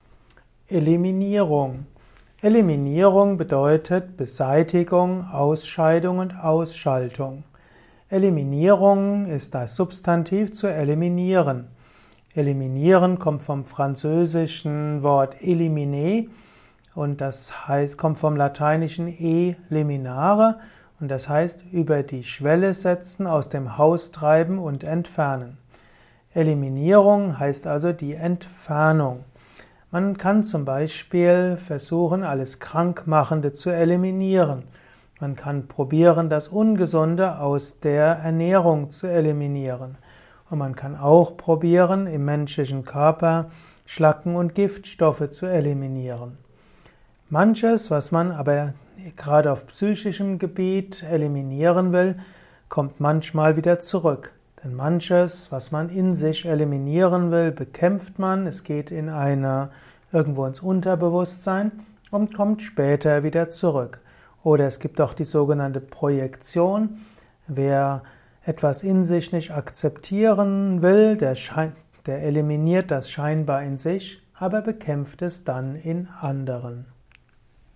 Ein Kurzvortrag zu der Eliminierung